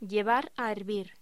Locución: Llevar a hervir
voz